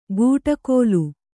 ♪ gūṭa kōlu